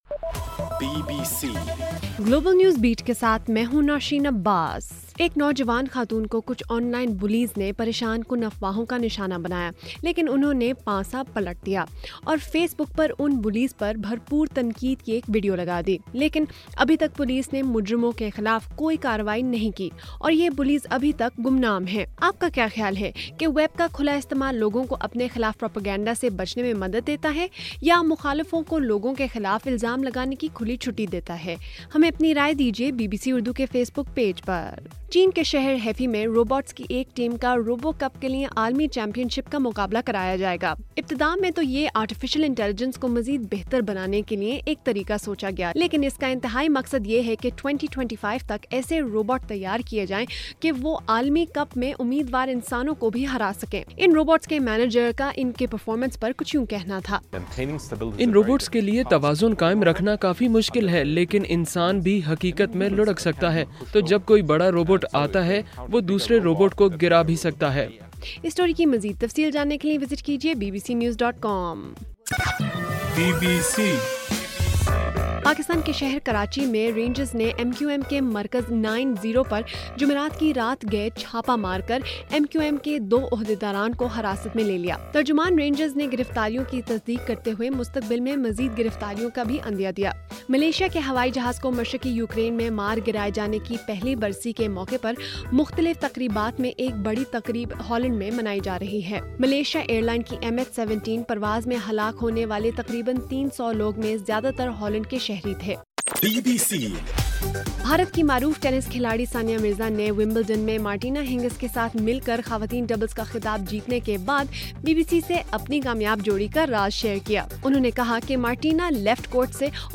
جولائی 17: رات 8 بجے کا گلوبل نیوز بیٹ بُلیٹن